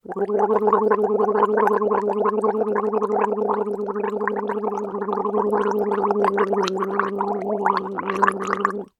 Human Gargle Female Sound Effect MP3 Download Free - Quick Sounds
Human Gargle Female